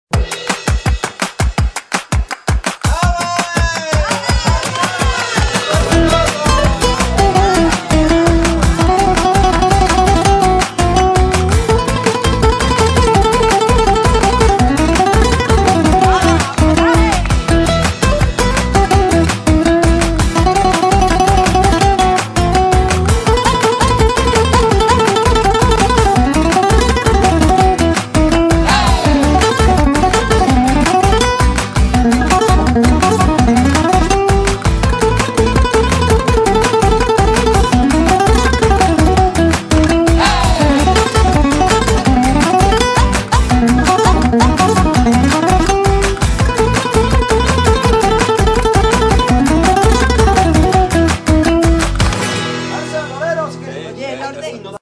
Купил себе звукавуху Коннект 6 и сталкнулся с такой проблемой: при записи вокала сильно много эхо в голосе записует...До этого работал со звукавухой ESI Maya 44 такой проблемы не наблюдалось...Использую микрофон Октава Мк - 319, запись проводится в необработанной комнате...